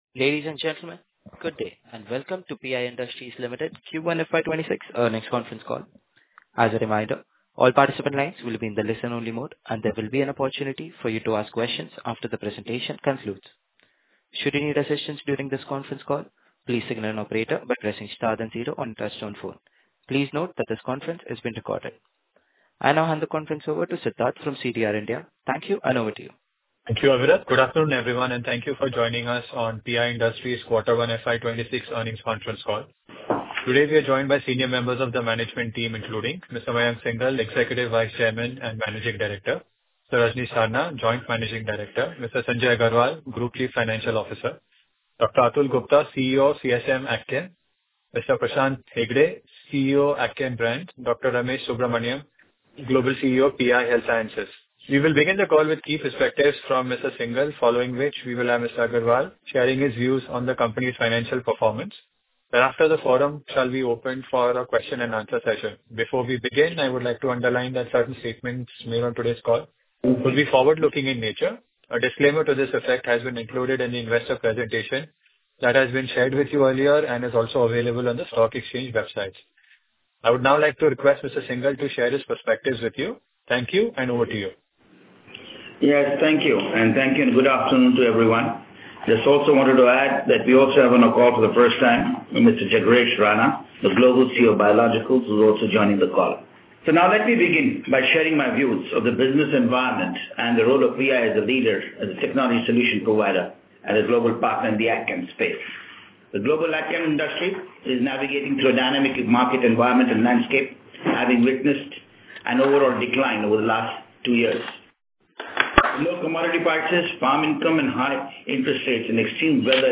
Audio Recording of Q1 FY26 Earnings conference call